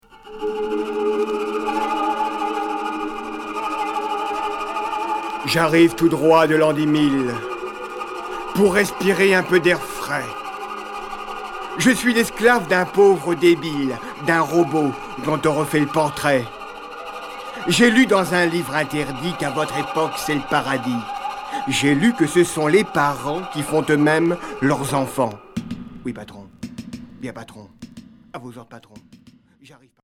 Folk expérimental